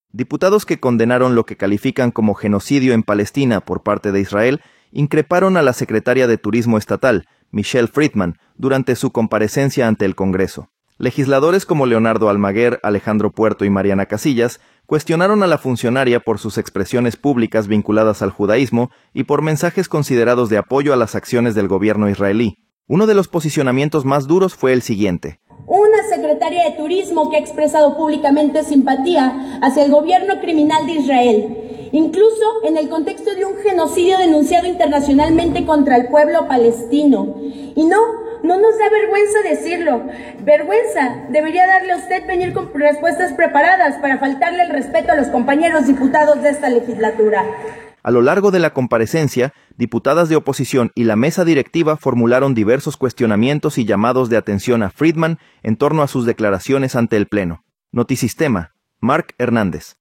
Diputados que condenaron lo que califican como genocidio en Palestina por parte de Israel increparon a la secretaria de Turismo estatal, Michelle Fridman, durante su comparecencia ante el Congreso. Legisladores como Leonardo Almaguer, Alejandro Puerto y Mariana Casillas cuestionaron a la funcionaria por sus expresiones públicas vinculadas al judaísmo y por mensajes considerados de apoyo a las acciones del gobierno israelí.